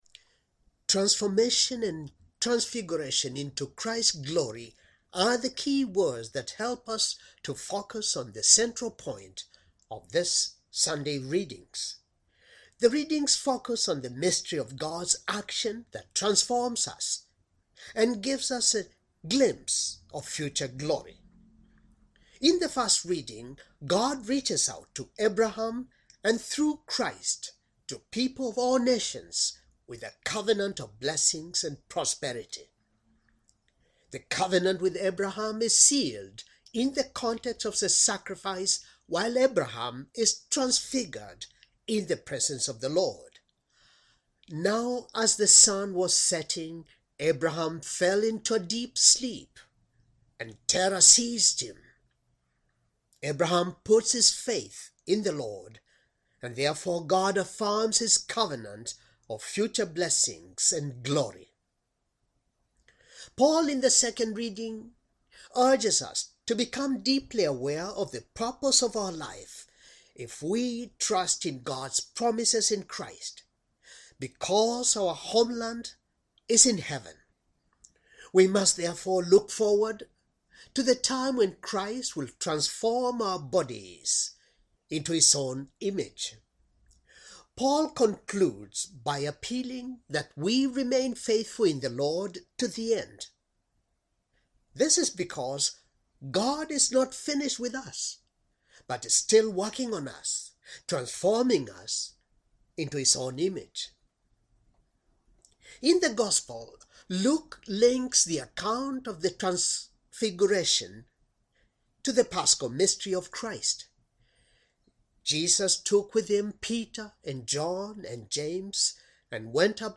Homily,second,sunday,lent,year,c,